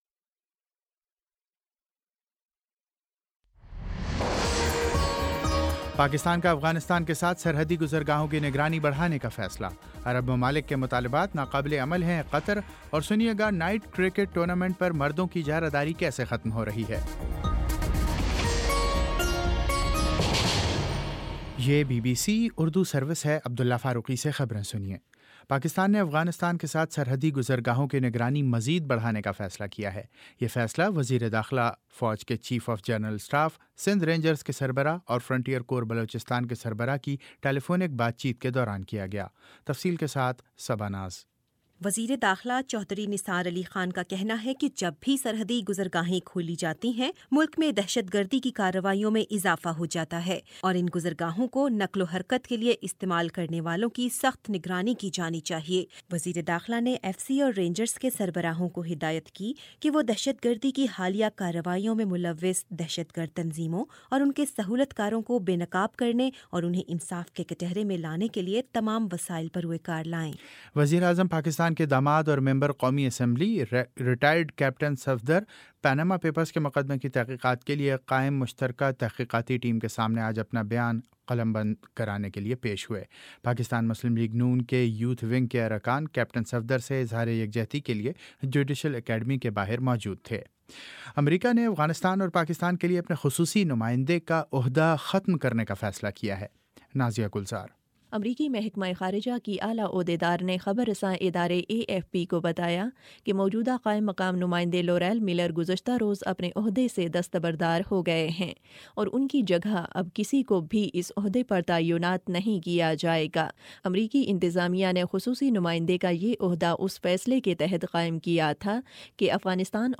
جون 24 : شام چھ بجے کا نیوز بُلیٹن